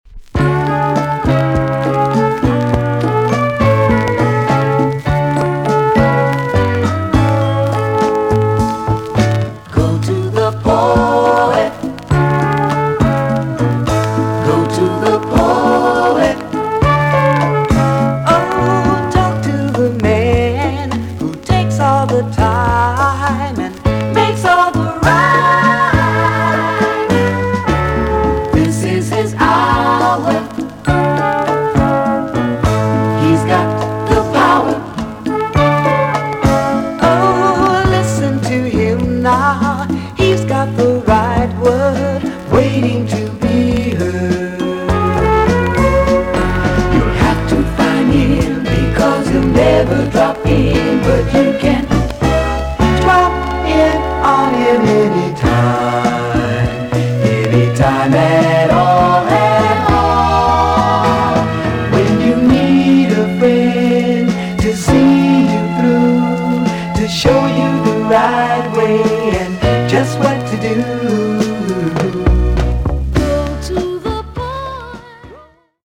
EX-~VG+ 少し軽いチリノイズがありますが良好です。